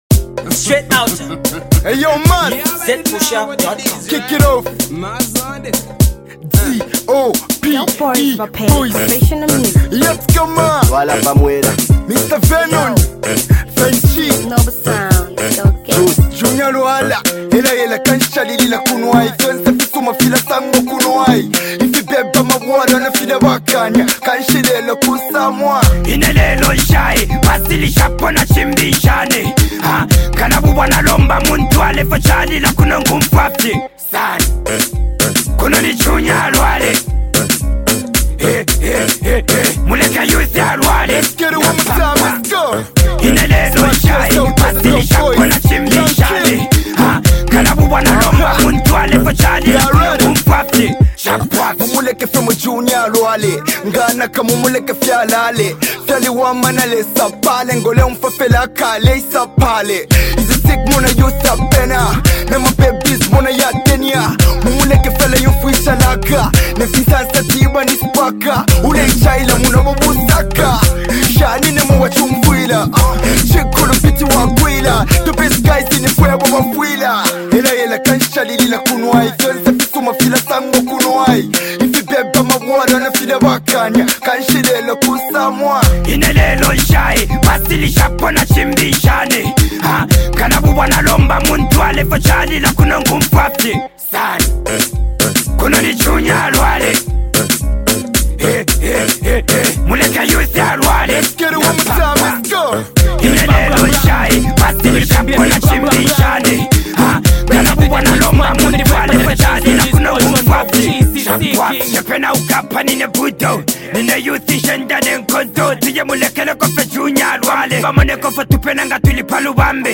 massive dancehall banger